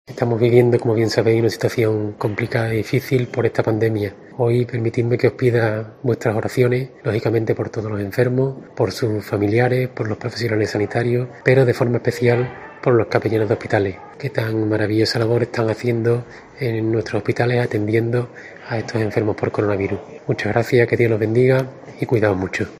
Oración